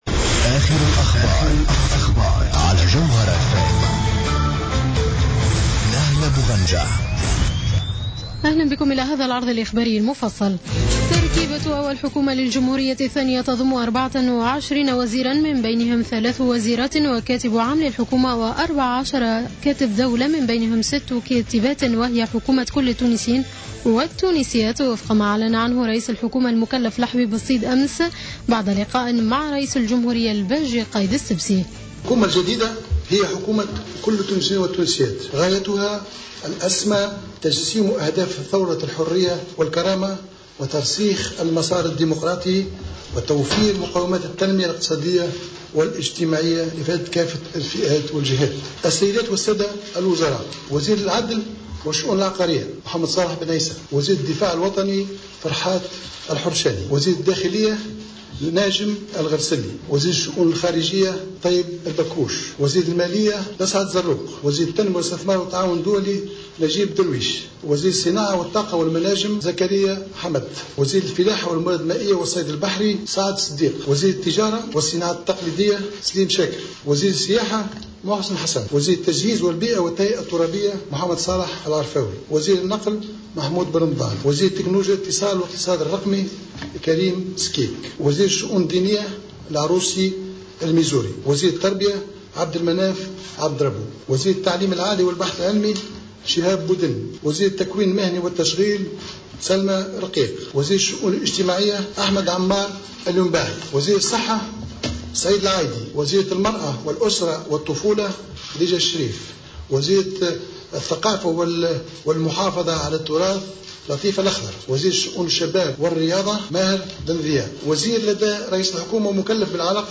نشرة أخبار منتصف الليل ليوم السبت 24 جانفي 2014